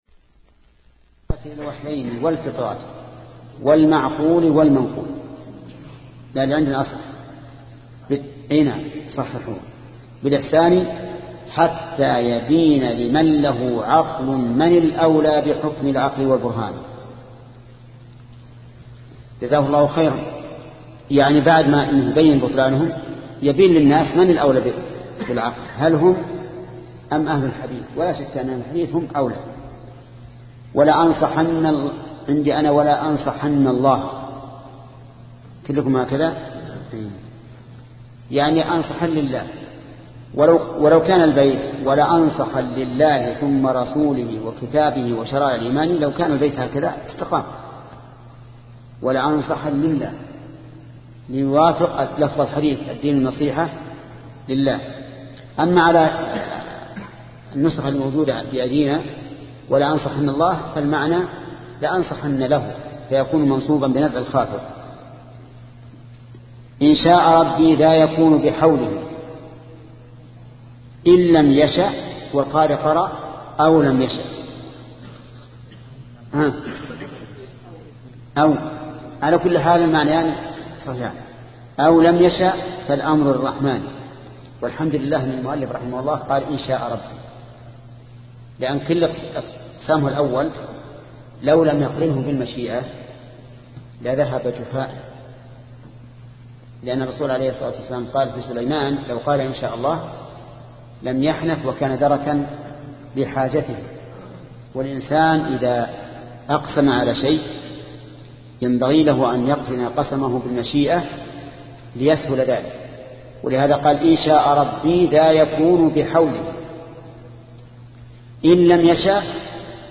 شبكة المعرفة الإسلامية | الدروس | التعليق على القصيدة النونية 30 |محمد بن صالح العثيمين